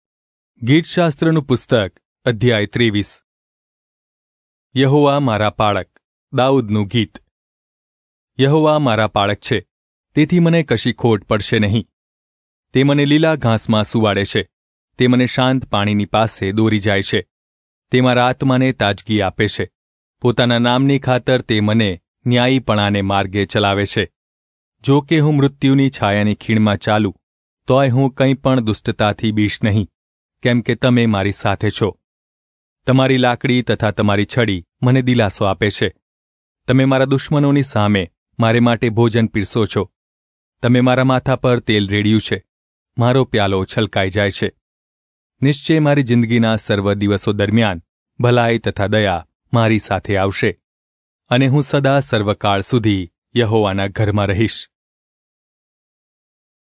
Gujarati Audio Bible - Psalms 3 in Irvgu bible version